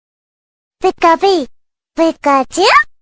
funny voice sms tone | funny sound message tone download